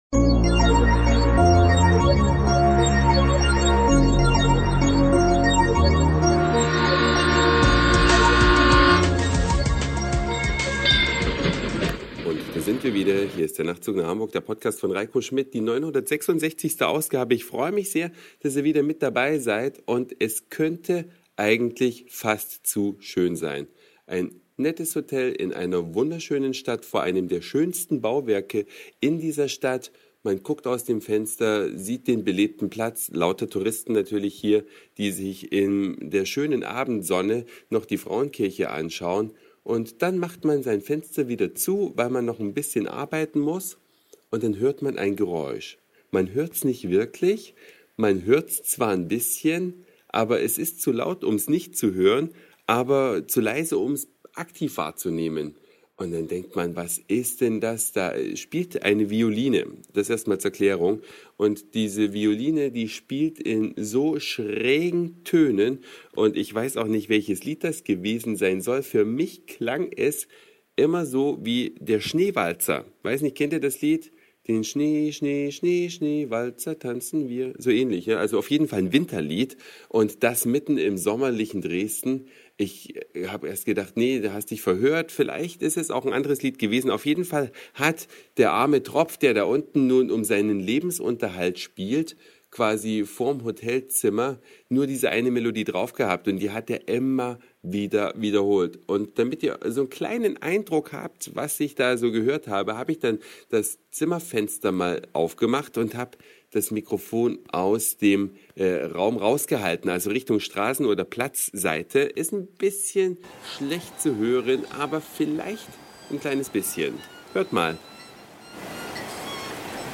Eine Reise durch die Vielfalt aus Satire, Informationen, Soundseeing und Audioblog.
Schön-schaurige Melodien vor der wunderschönen Dresdner